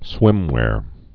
(swĭmwâr)